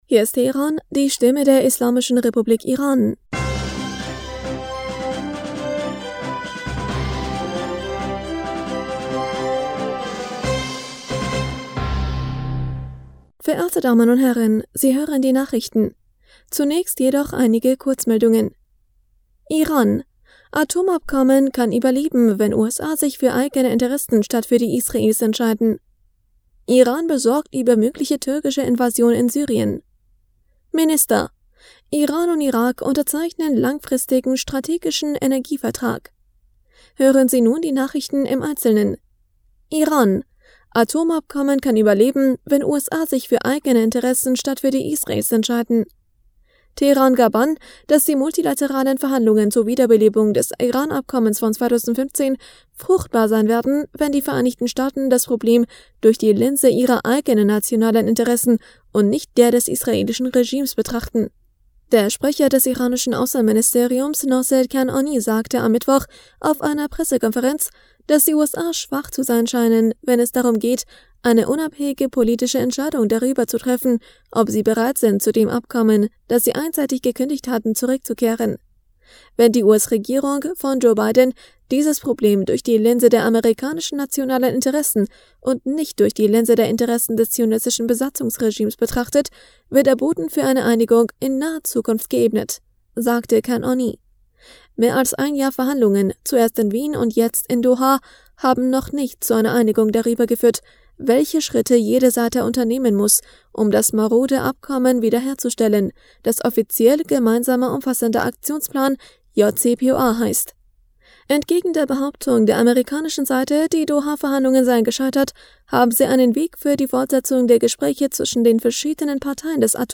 Nachrichten vom 21. Juli 2022